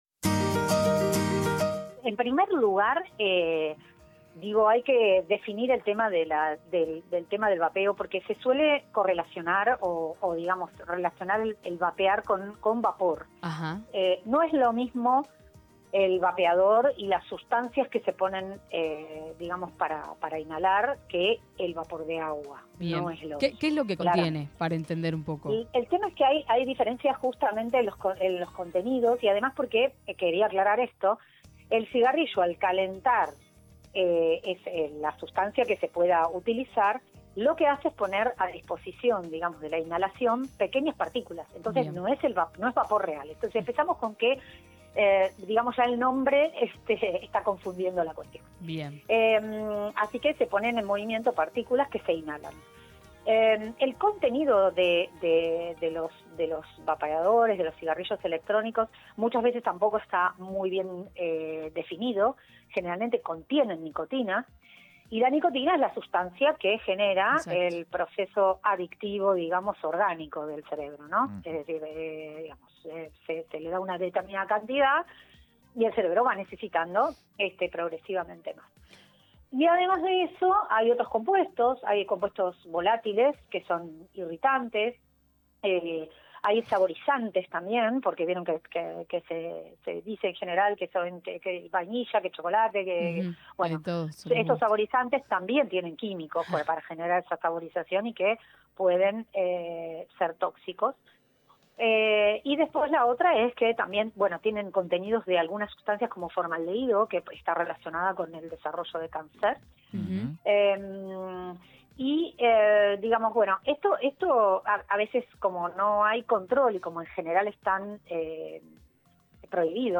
médica Neumonóloga